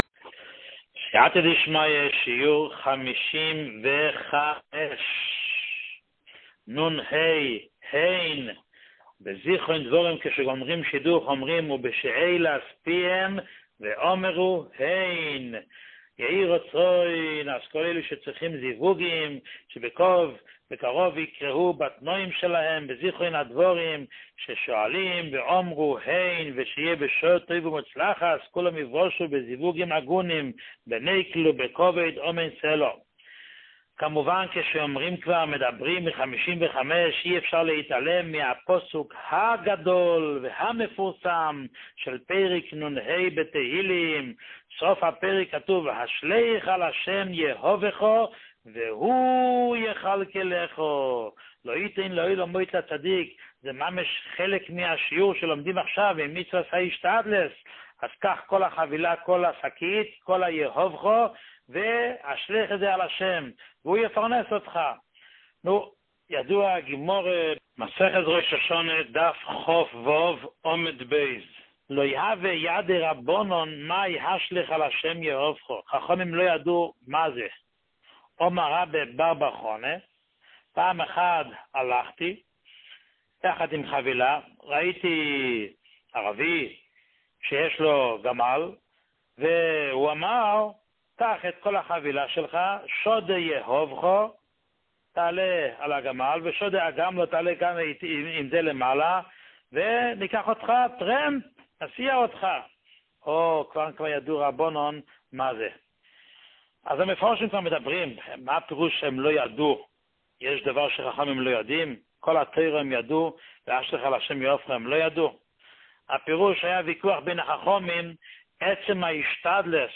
שיעורים מיוחדים
שיעור 55